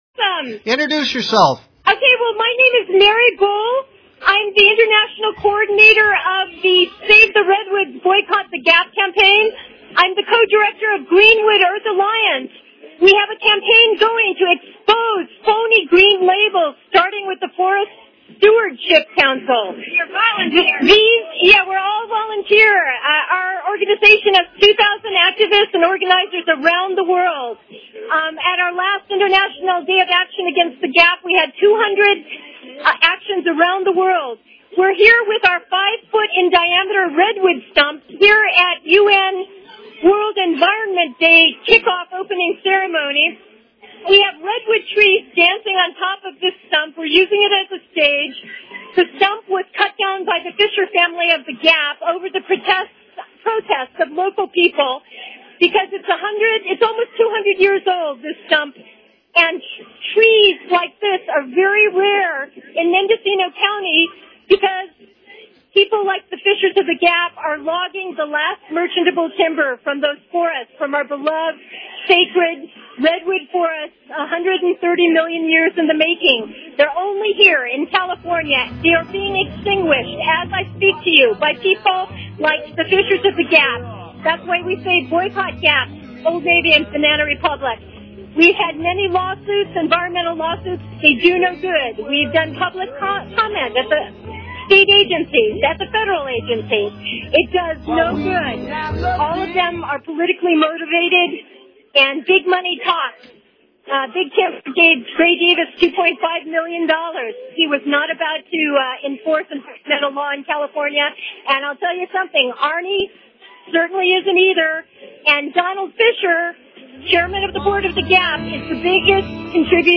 World Environment Day Interview